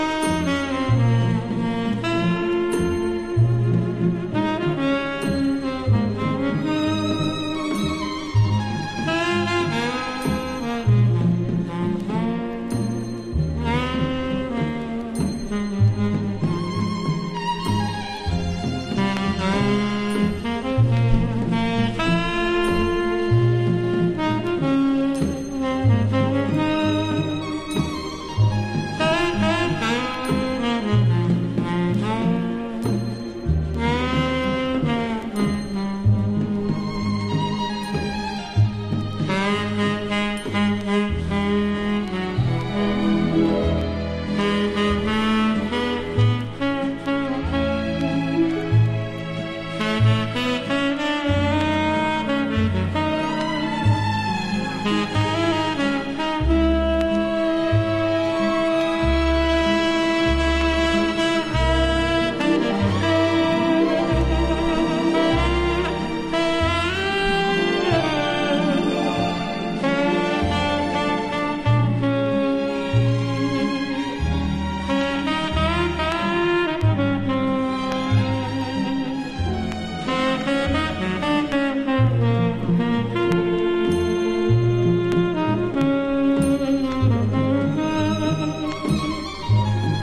ジャケットも秀逸な極上のムード音楽！
スタンダードもいつになくムーディーに。